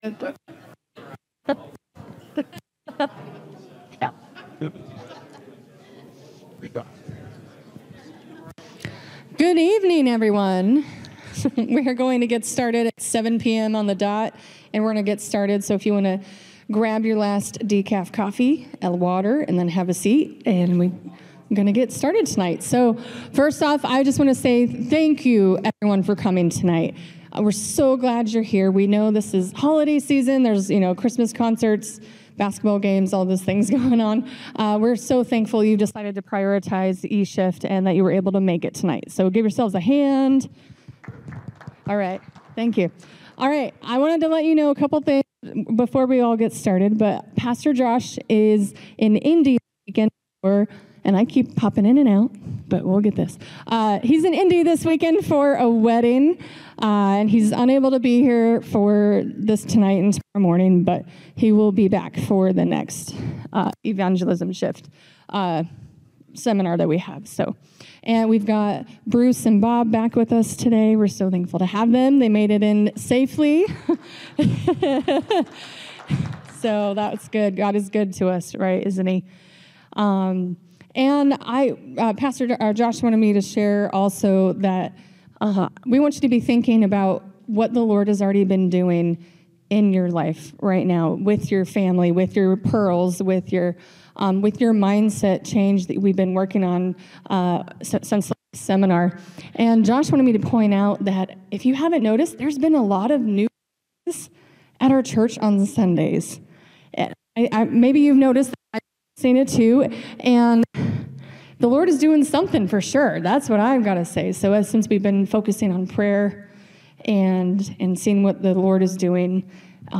Seminar recordings from Evangelism Shift.